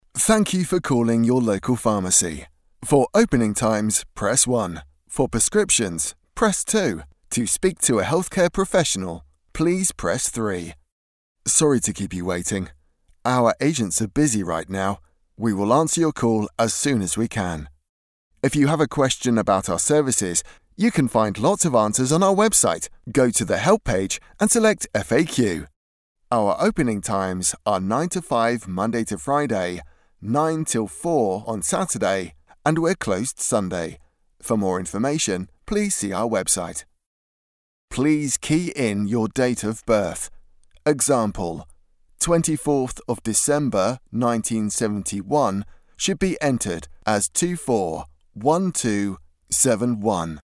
Natürlich, Cool, Zugänglich, Unverwechselbar, Warm
Telefonie